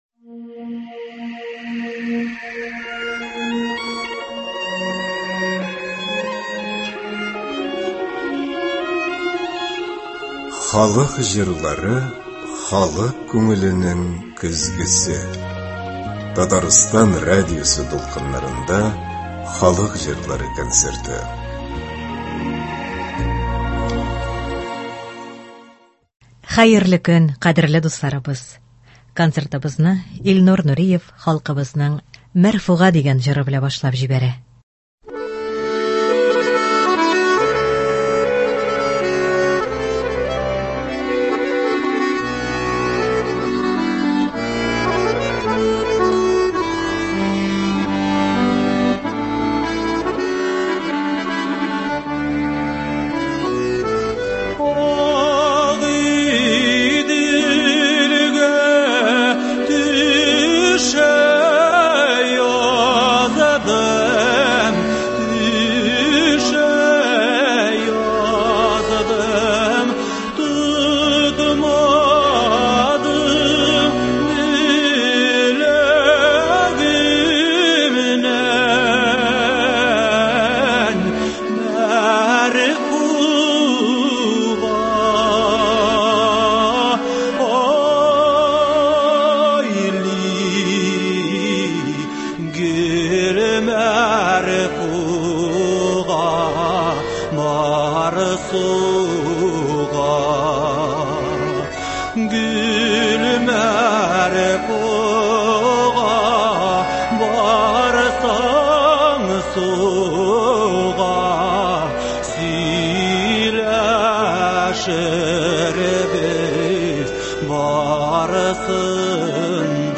Татар халык көйләре (17.06.23)